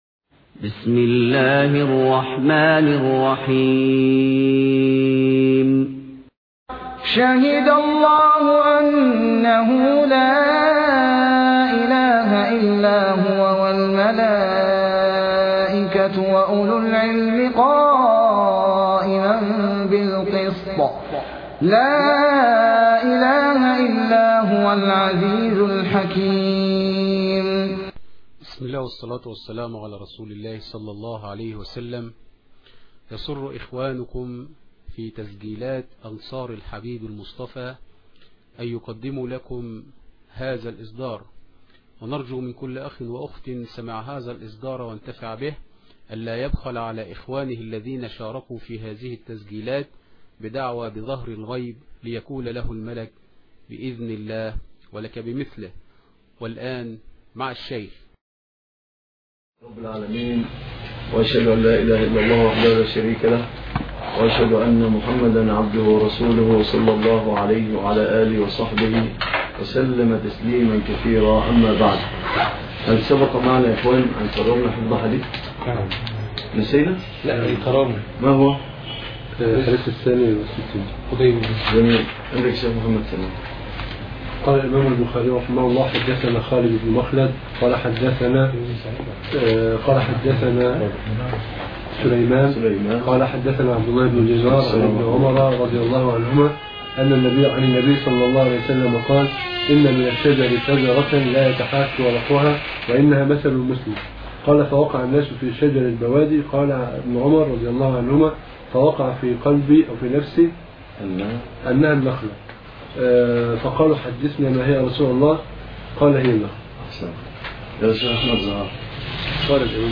الدرس (2) شرح كتاب العلم